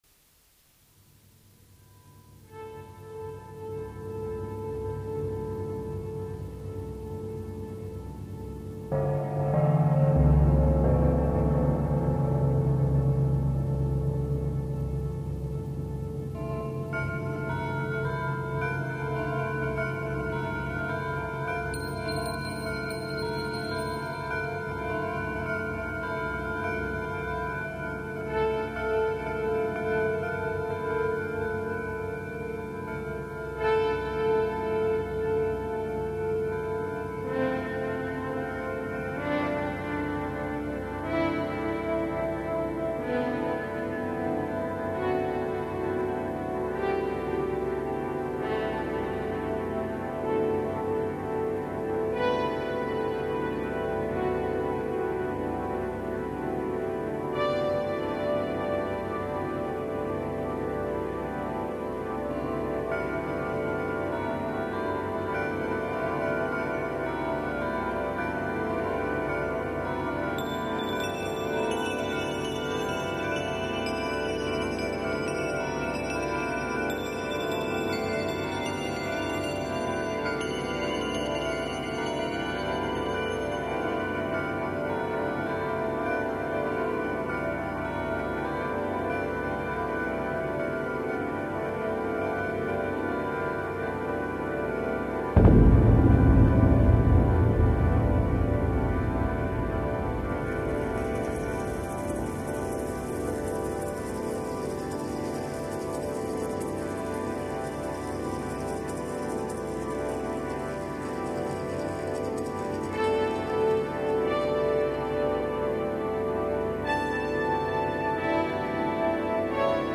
piano, keyboards & percussion